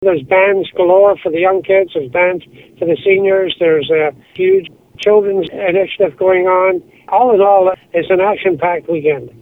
Sweet says there will be something for everyone in the family to enjoy from young children to seniors and he adds it will be an action packed weekend.